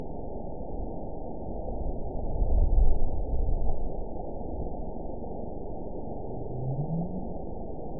event 920349 date 03/18/24 time 03:09:35 GMT (1 year, 1 month ago) score 9.64 location TSS-AB01 detected by nrw target species NRW annotations +NRW Spectrogram: Frequency (kHz) vs. Time (s) audio not available .wav